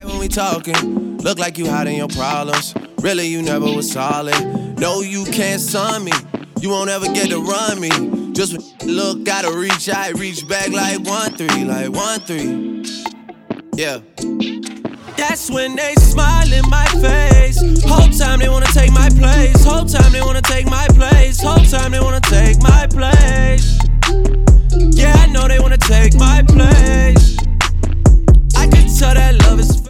• Hip-Hop/Rap
features an R&B beat